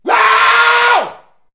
SCREAM.wav